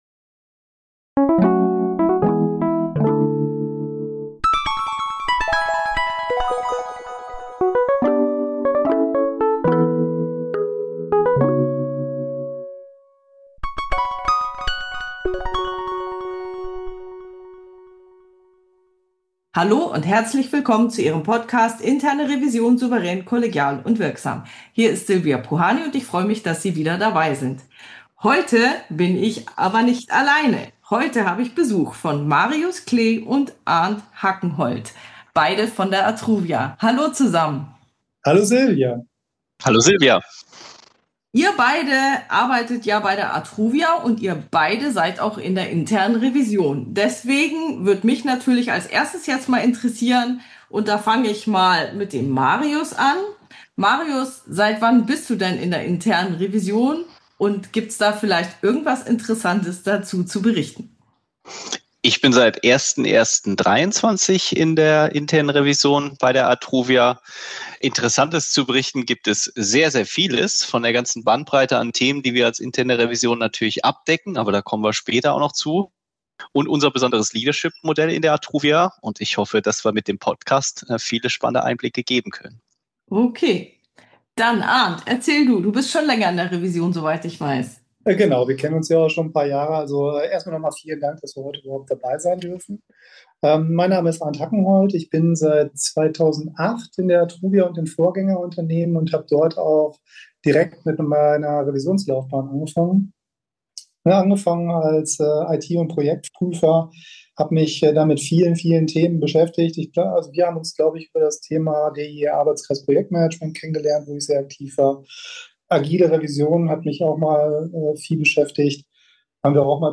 #403: Interview über Shared Leadership in der Internen Revision ~ Interne Revision – souverän, kollegial und wirksam Podcast